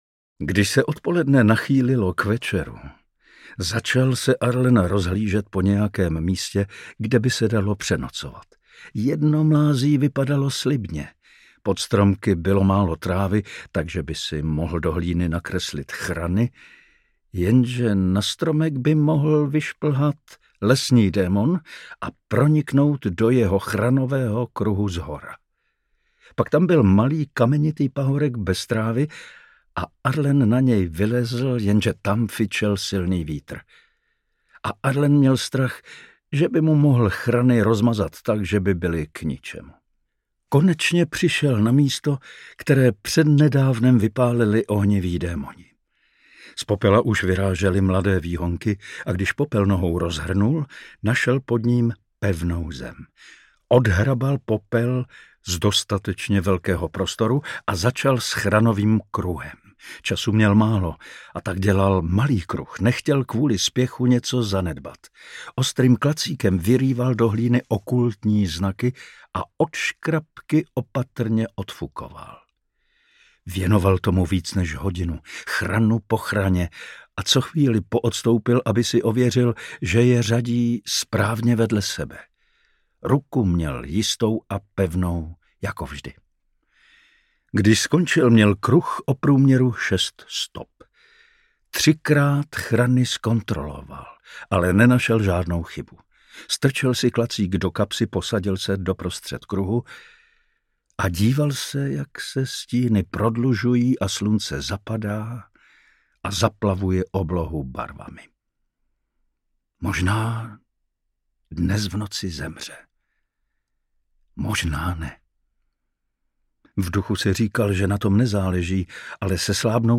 Tetovaný audiokniha
Ukázka z knihy